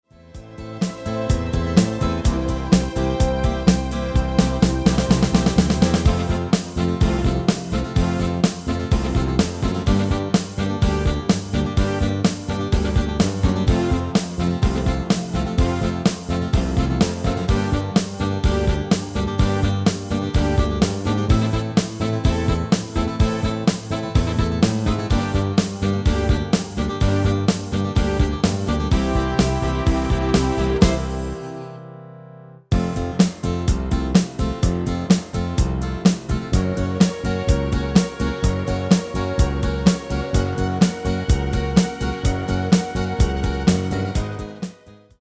Demo/Koop midifile
Genre: Nederlands amusement / volks
Toonsoort: F/F#
- Vocal harmony tracks